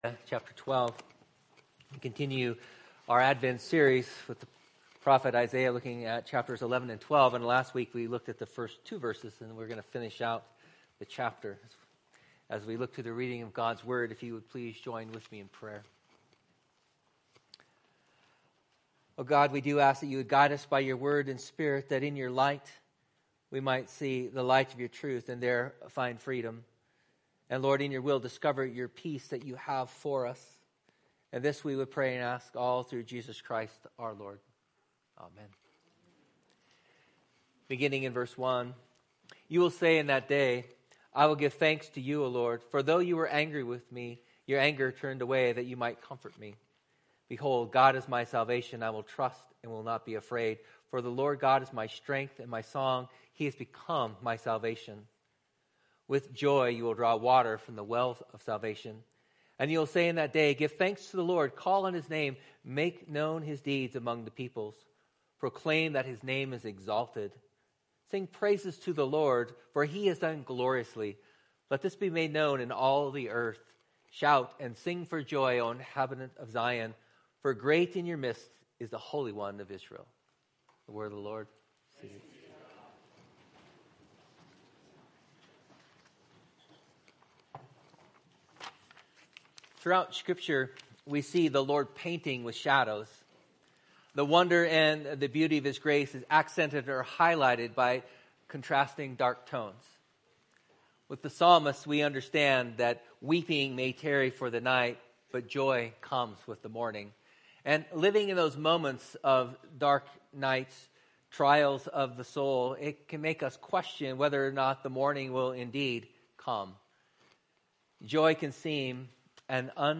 Type Morning